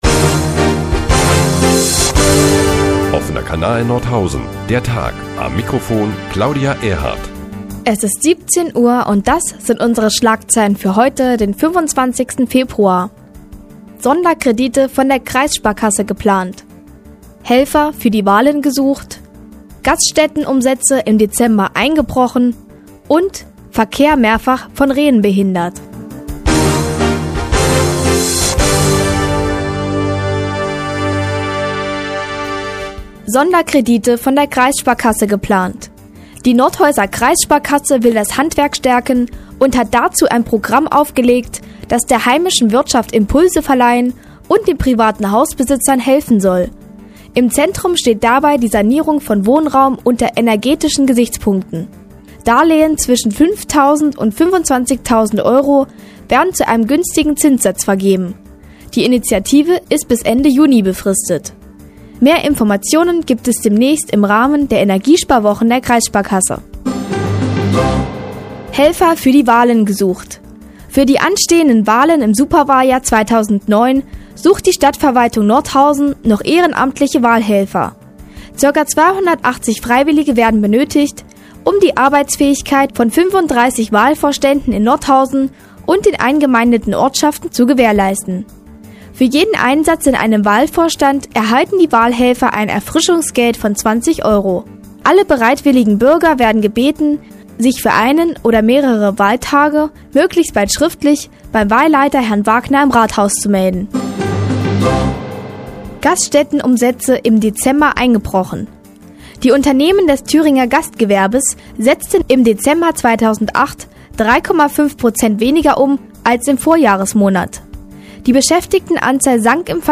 Die tägliche Nachrichtensendung des OKN ist nun auch in der nnz zu hören. Heute unter anderem mit geplanten Sonderkrediten der Kreissparkasse und der Suche nach Helfern für anstehende Wahlen.